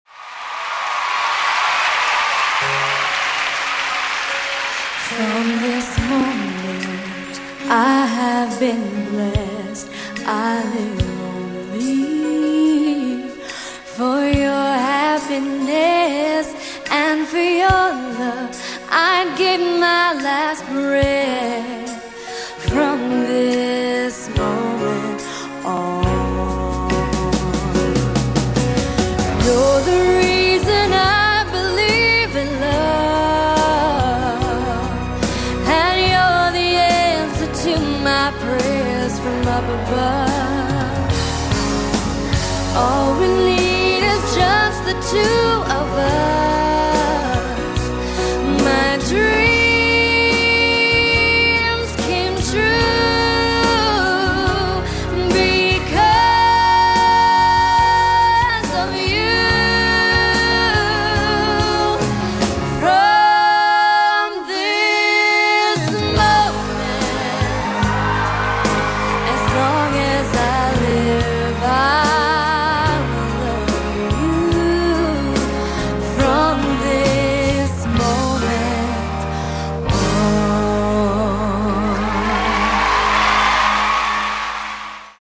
They should now  be higher quality and have the same volume.